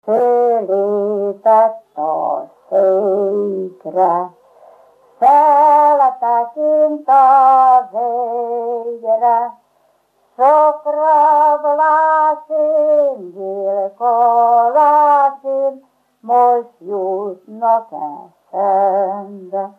Erdély - Udvarhely vm. - Korond
Műfaj: Ballada
Stílus: 8. Újszerű kisambitusú dallamok
Kadencia: VII (b3) 4 1